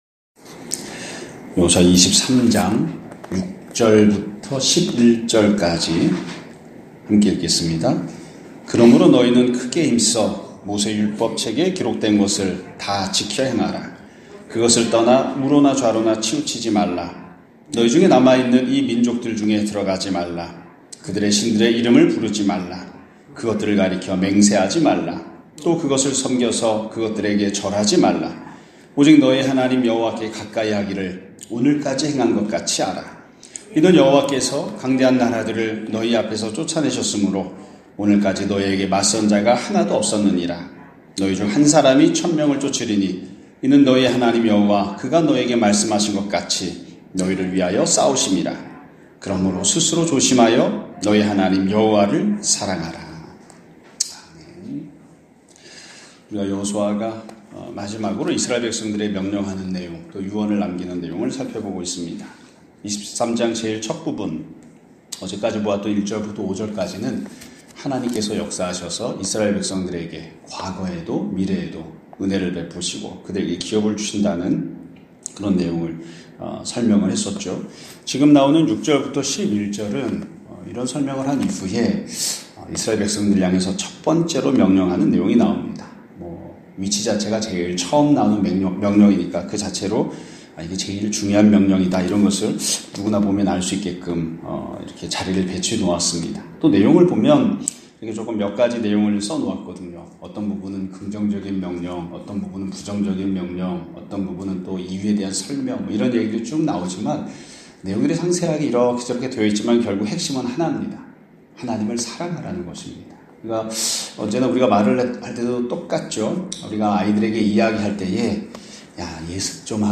2025년 2월 18일(화요일) <아침예배> 설교입니다.